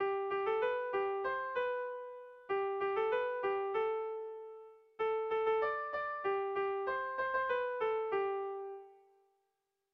Sehaskakoa
AB